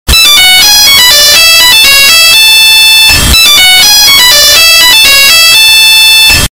Nokia Ringtone Earrape